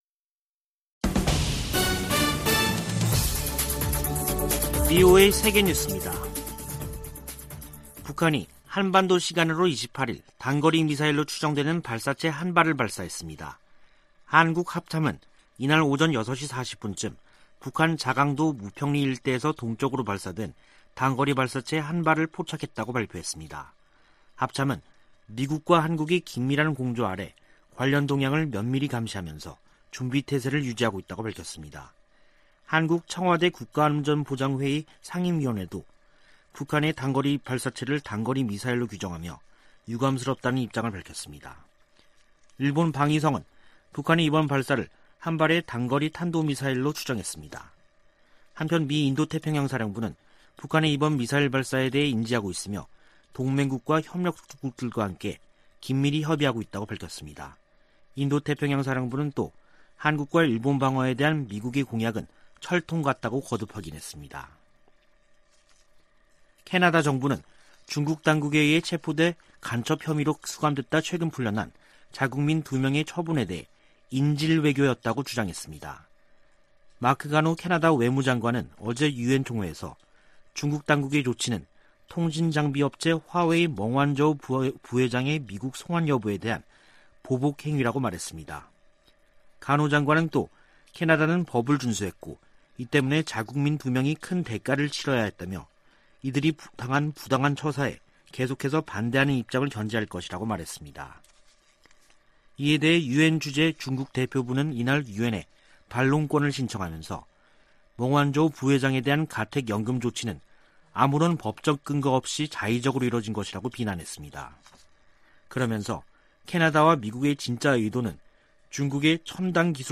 VOA 한국어 간판 뉴스 프로그램 '뉴스 투데이', 2021년 9월 28일 2부 방송입니다. 북한은 김여정 노동당 부부장이 대남 유화 담화를 내놓은 지 사흘 만에 단거리 미사일 추정 발사체를 동해 쪽으로 발사했습니다. 미 국무부는 북한의 발사체 발사를 규탄했습니다. 유엔주재 북한 대사가 미국에 대북 적대시 정책을 철회하라고 요구했습니다.